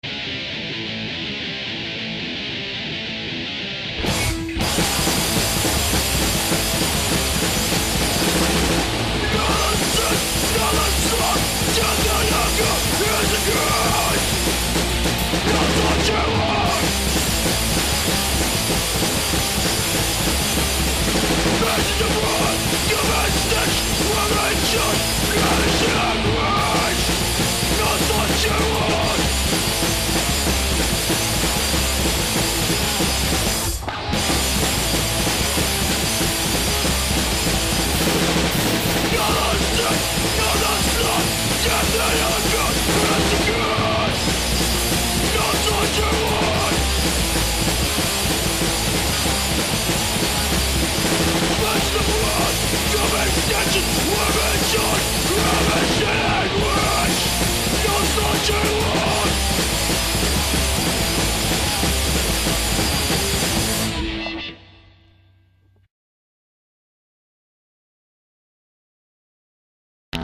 A D-beat / Crustcore Onslaught !!